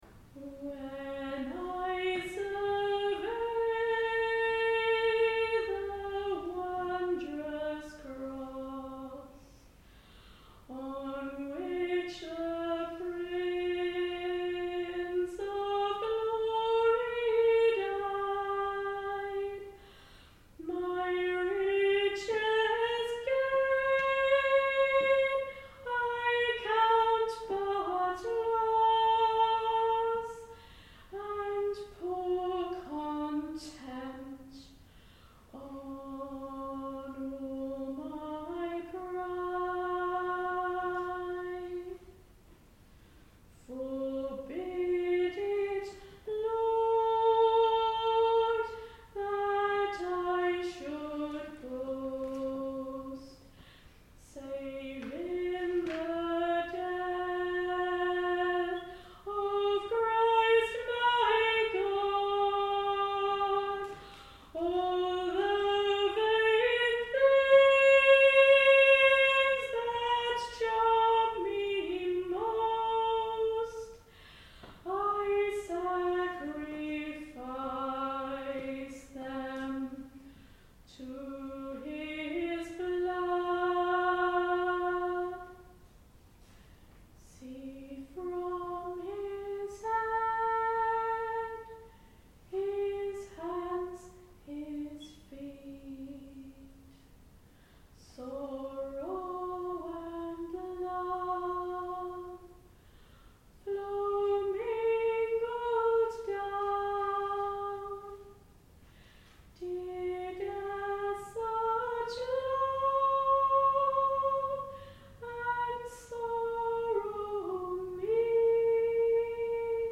When I Survey the Wondrous Cross (Waly Waly) - my third solo and the final hymn at a Good Friday service on 18 April 2025